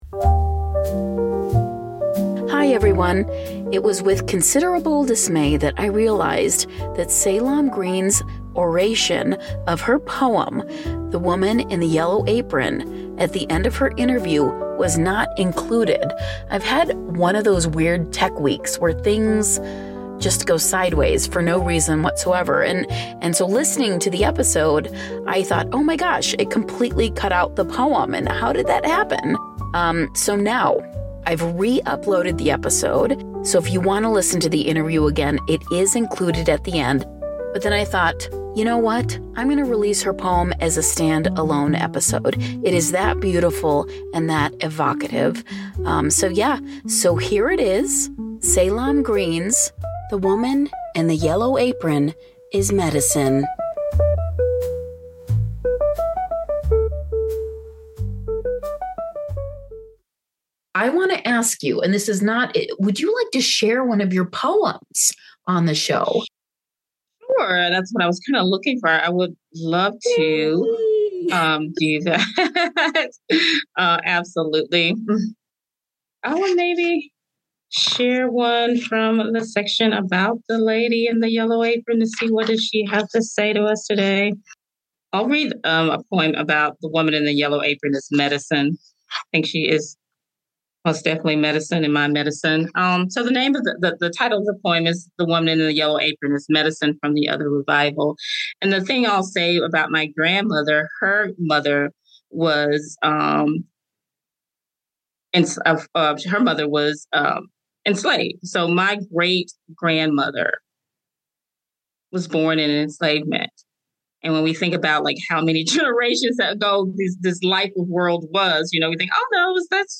But that's OK, because it is allowing me to showcase her poem in it's entirety here in it's own separate little episode.